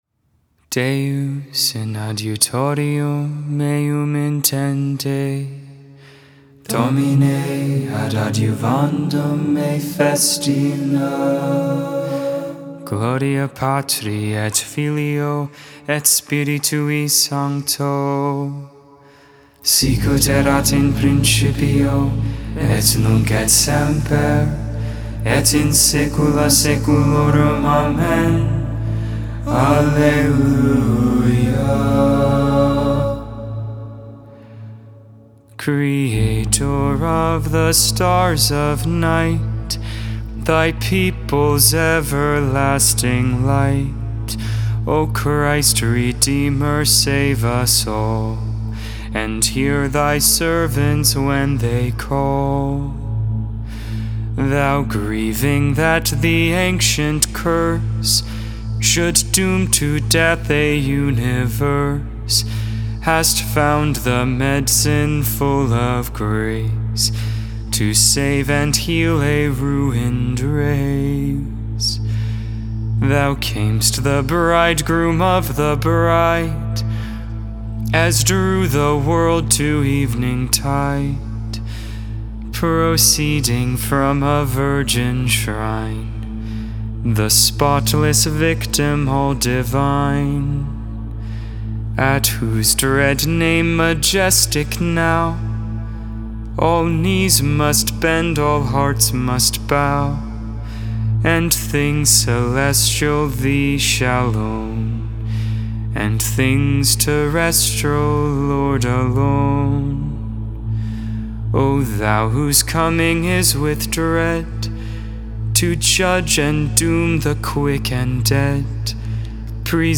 12.19.21 Vespers, Sunday Evening Prayer